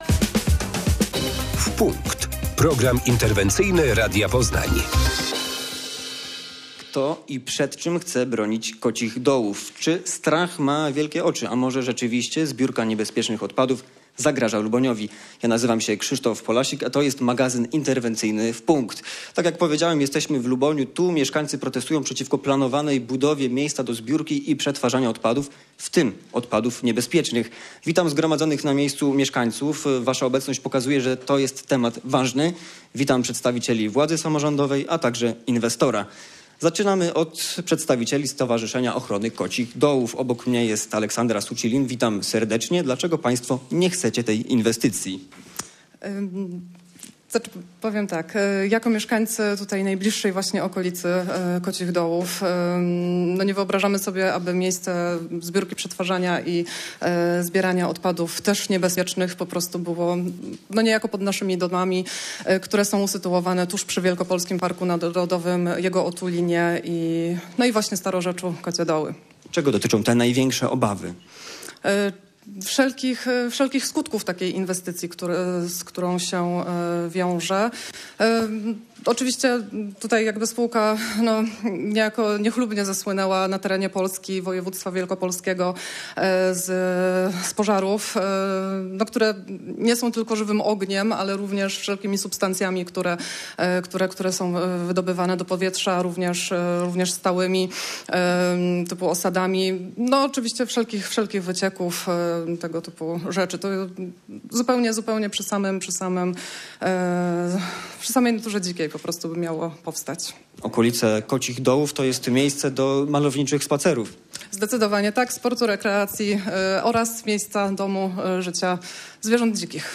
Taką deklarację złożyła podczas magazynu "W punkt" burmistrz podpoznańskiej gminy Małgorzata Machalska. Zakład zbierania i przetwarzania odpadów obok jeziora Kocie Doły chce postawić firma Polcopper.